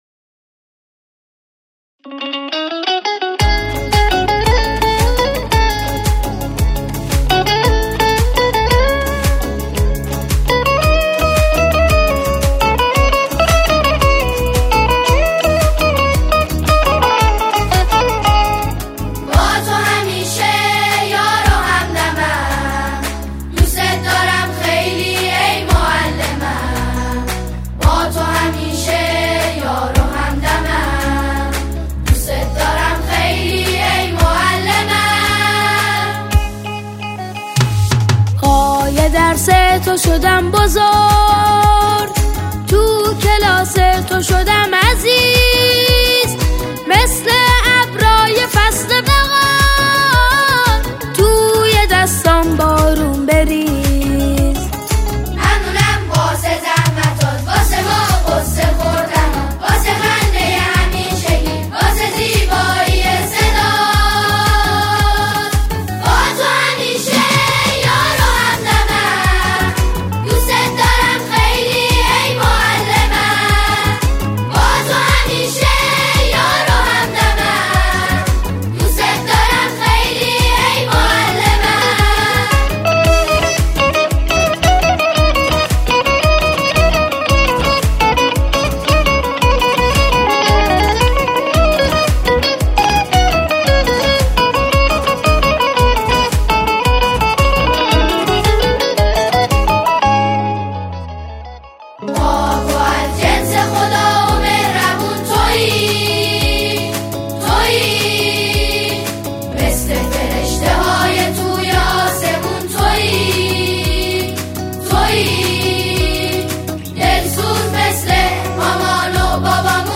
سرودهای دانش آموزی